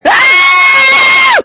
JUST SCREAM! Screams from December 1, 2020
• When you call, we record you making sounds. Hopefully screaming.